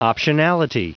Prononciation du mot optionality en anglais (fichier audio)
Prononciation du mot : optionality